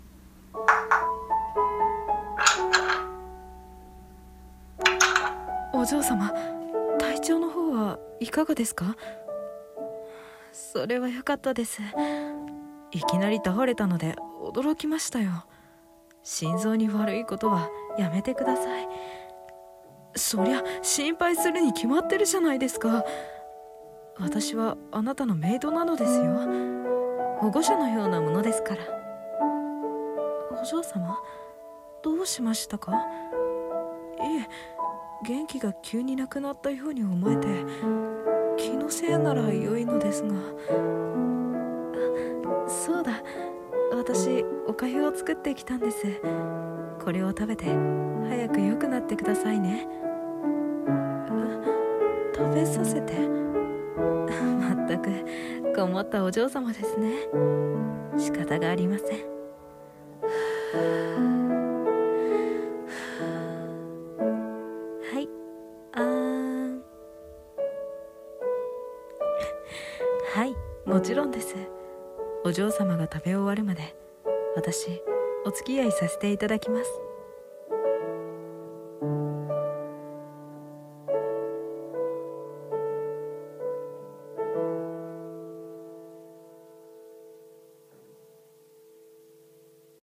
[一人声劇] 看病執事orメイド